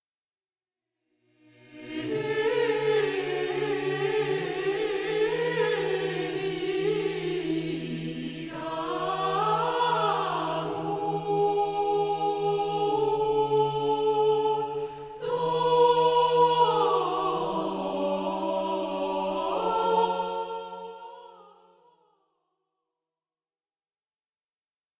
Organum